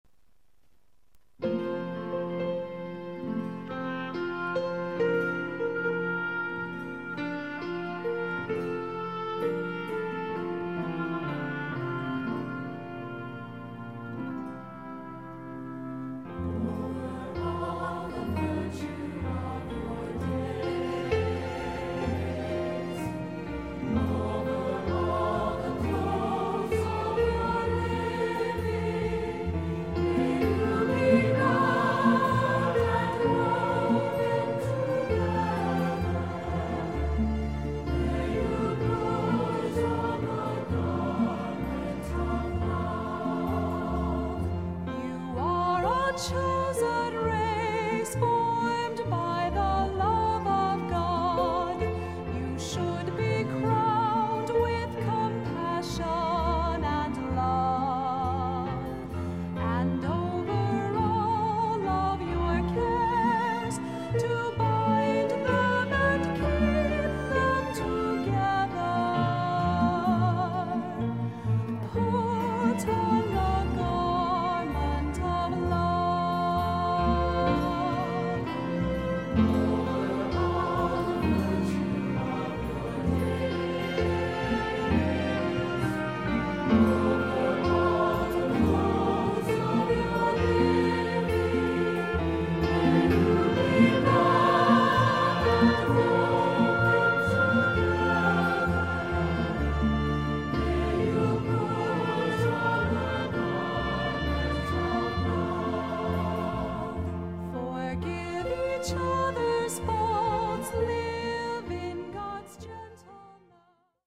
Voicing: Cantor, assembly